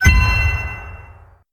coin collect geometry dash Meme Sound Effect
Category: Games Soundboard
coin collect geometry dash.mp3